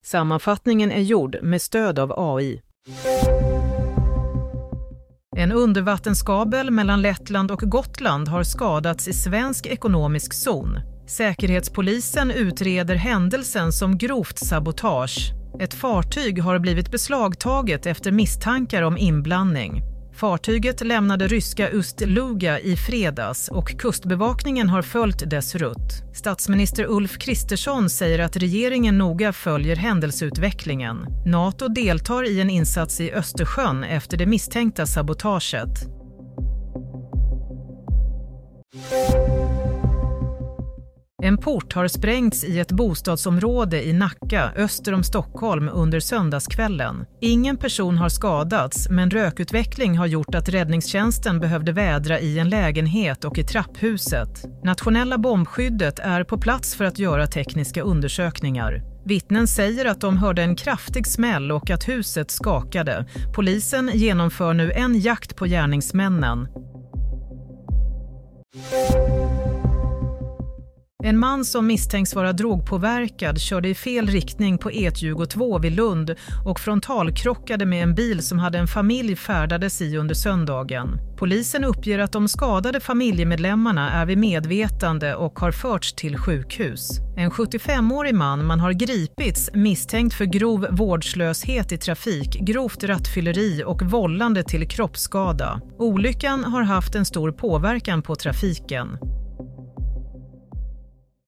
Play - Nyhetssammanfattning – 26 januari 22.00
Sammanfattningen av följande nyheter är gjord med stöd av AI. - Fartyg i beslag efter nytt misstänkt kabelbrott - Port sprängd i Nacka - Flera till sjukhus efter frontalkrock på E22 - Broadcast on: 26 Jan 2025